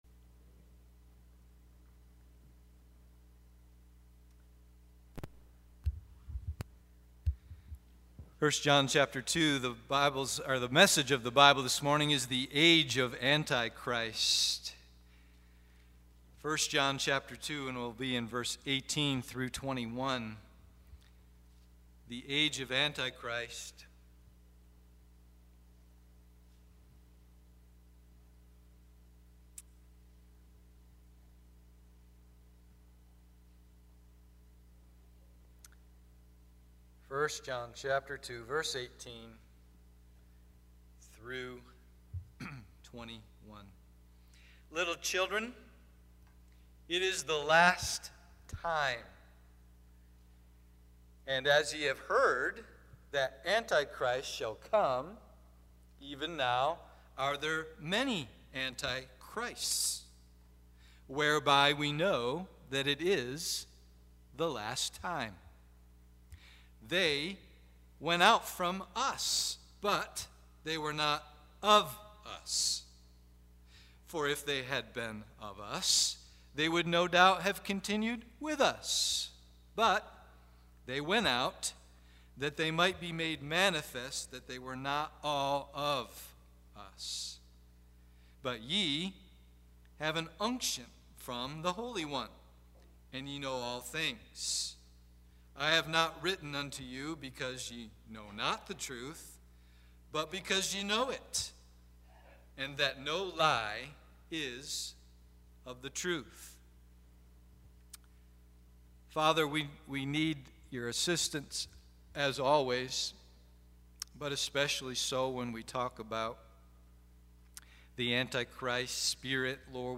The Age of Antichrist AM Service